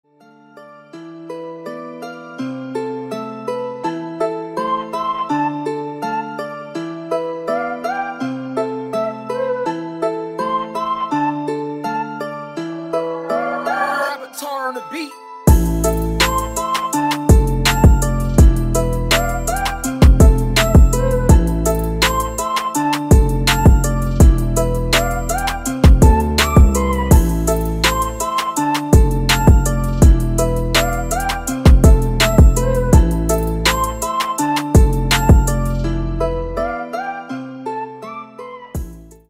• Качество: 160, Stereo
Trap, EDM, Hip-Hop.